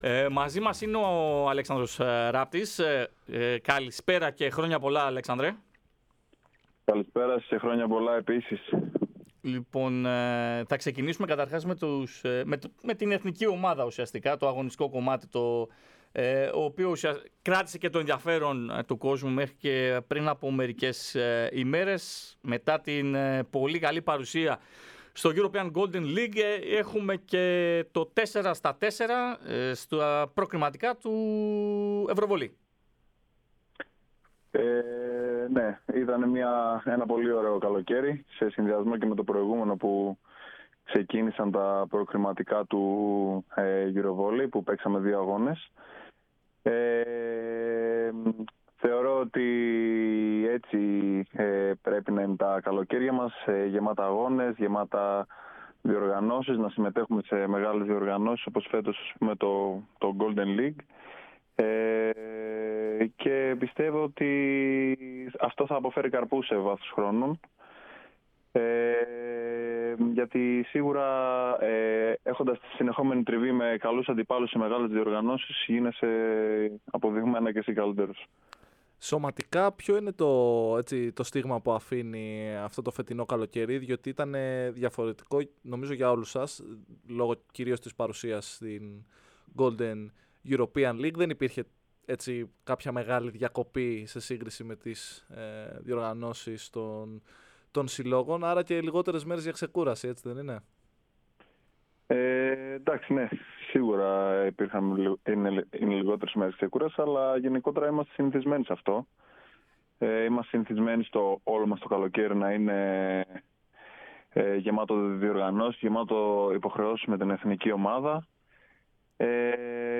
Ο διεθνής ακραίος παραχώρησε συνέντευξη στην εκπομπή "Match Point" της κορυφαίας αθλητικής συχνότητας της χώρας, λίγες ημέρες μετά την ολοκλήρωση των υποχρεώσεών του με το αντιπροσωπευτικό μας συγκρότημα.